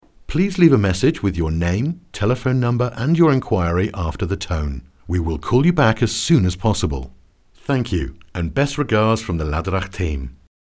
Profonde, Distinctive, Mature, Chaude, Corporative
E-learning